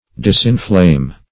Disinflame \Dis`in*flame"\